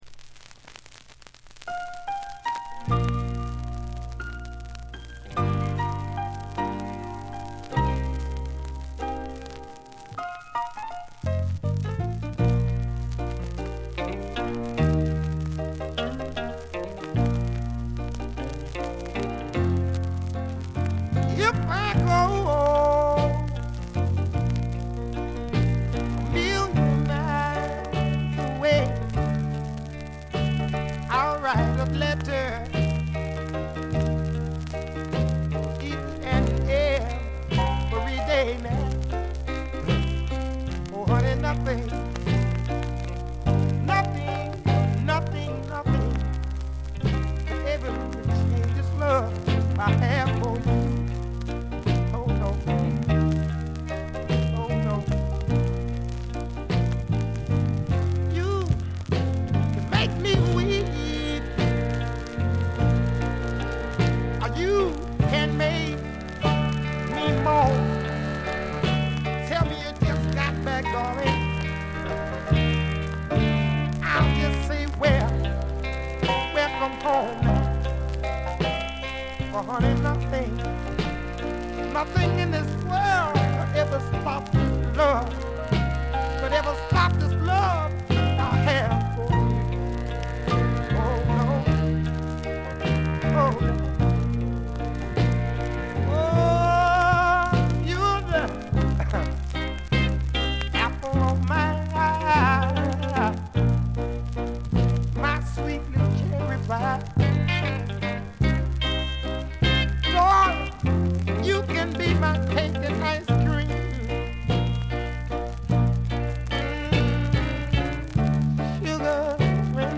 全編通じて強めのバックグラウンドノイズが出ます。
音質はよくありませんがコレクターの方はお見逃しなく。
試聴曲は現品からの取り込み音源です。
vocals